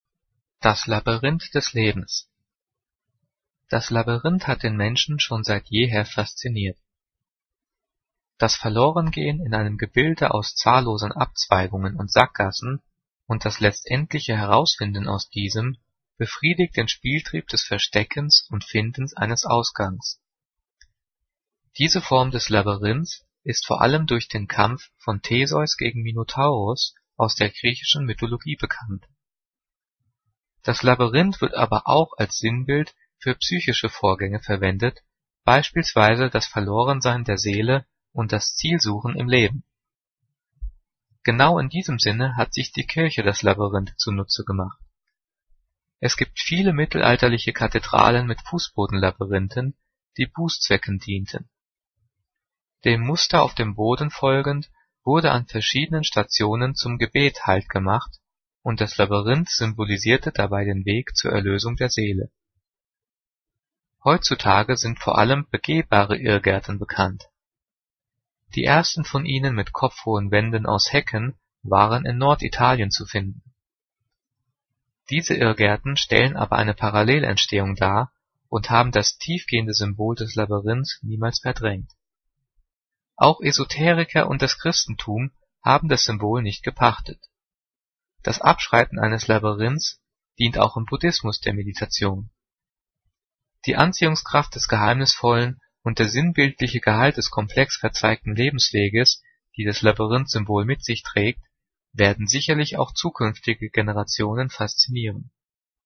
Diktat: "Das Labyrinth des Lebens" - 9./10. Klasse - Getrennt- und Zus.
Gelesen: